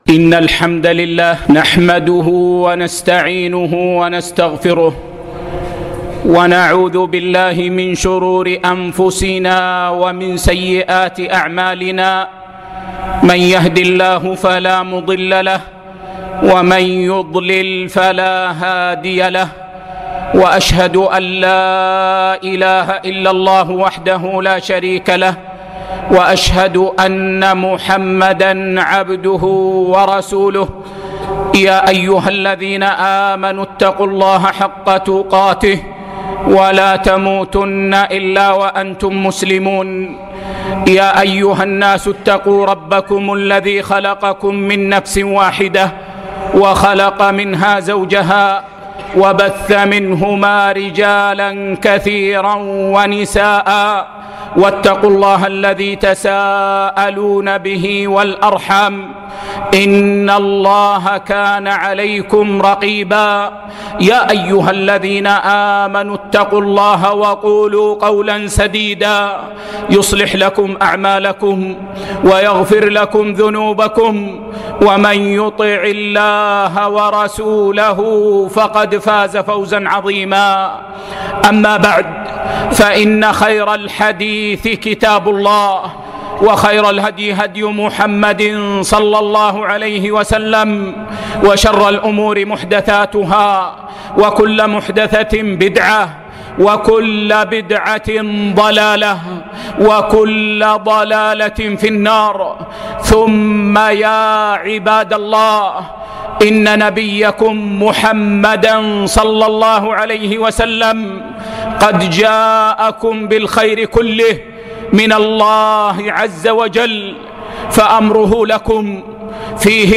خطبة بعنوان: الأخلاق لـ